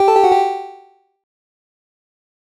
フリー効果音：システム31
コンピューター系効果音第31弾！何かの起動音や読み込みのシーンにぴったりです！